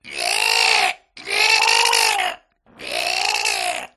モンスターの音、モンスターの悲鳴
• カテゴリー: モンスター